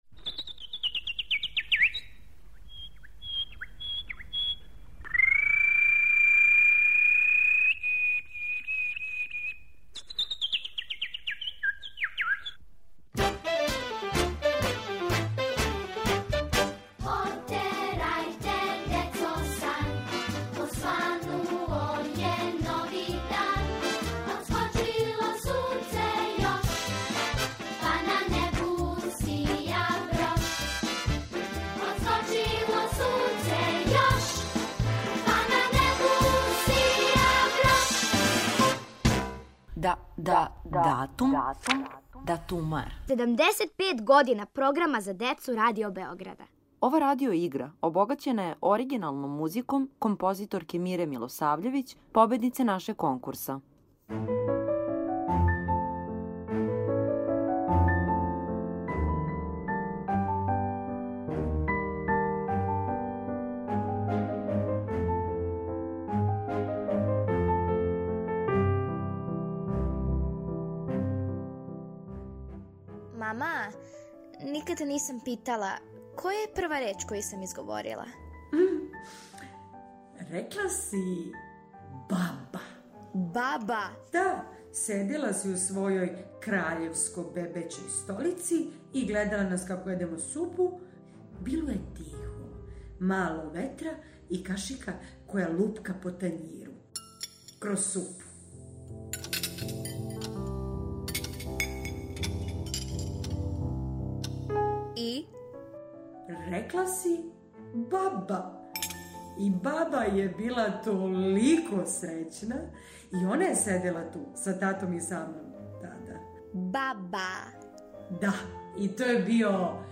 будимо вас трећим делом радио игре